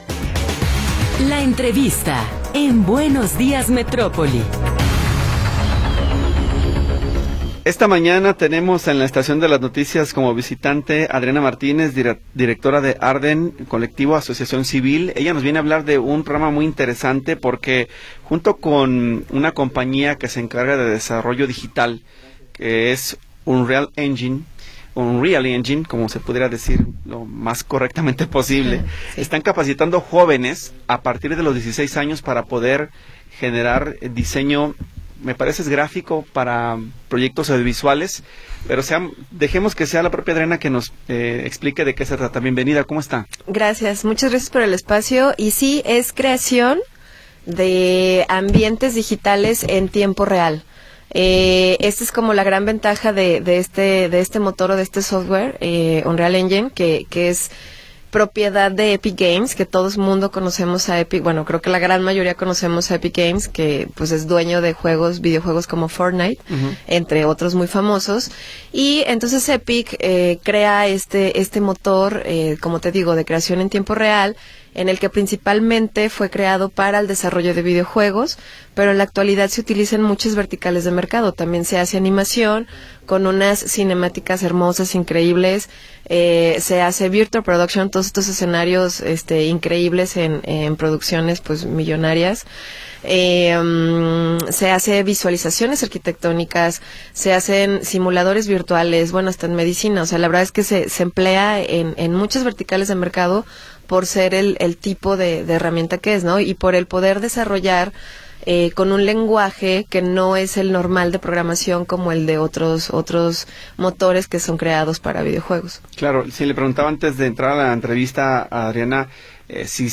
entrevista.m4a